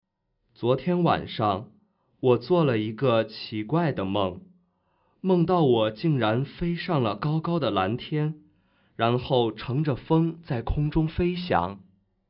口音（男声）